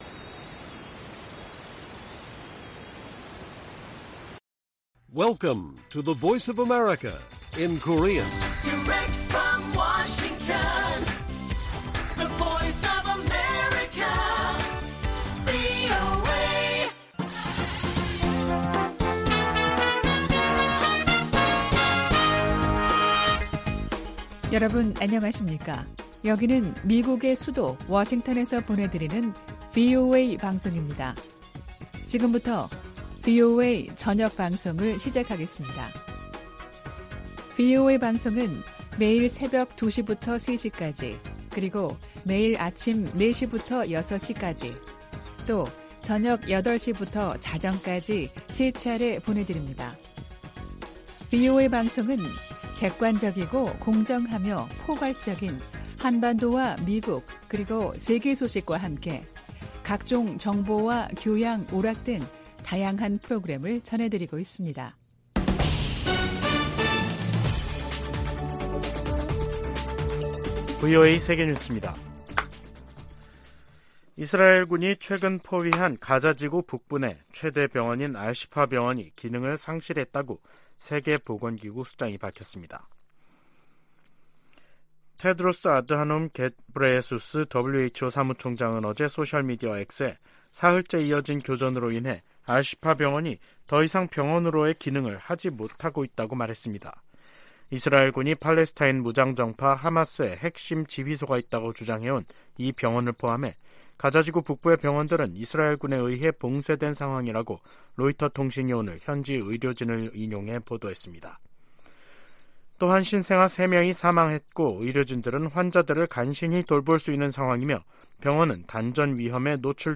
VOA 한국어 간판 뉴스 프로그램 '뉴스 투데이', 2023년 11월 13일 1부 방송입니다. 미국과 한국은 북한의 핵 위협에 대응해 맞춤형 억제전략(TDS)을 10년만에 개정하고, 미군 조기경보위성 정보 공유를 강화하기로 했습니다. 두 나라는 또 사이버안보 분야의 협력 강화를 위한 업무협약을 맺었습니다. 미국은 오는 15일의 미중 정상회담에서 북러 무기거래와 북한의 도발에 대한 우려, 한반도 비핵화 결의 등을 강조할 것이라고 밝혔습니다.